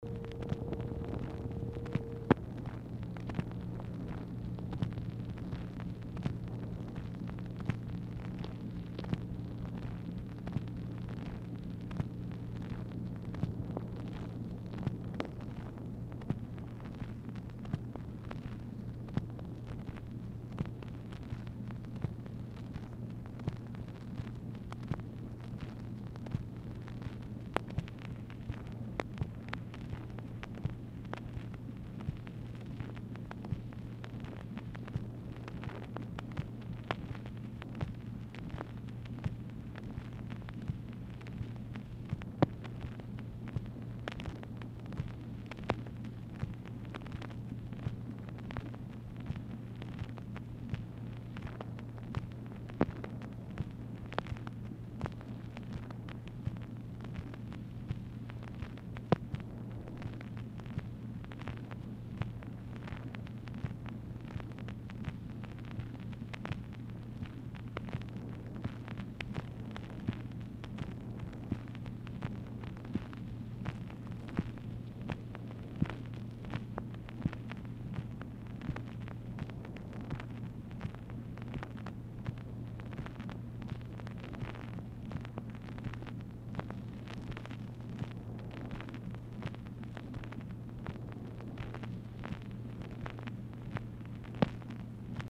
Telephone conversation # 4984, sound recording, MACHINE NOISE, 8/17/1964, time unknown | Discover LBJ
Telephone conversation
Dictation belt